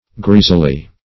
Greasily \Greas"i*ly\, adv.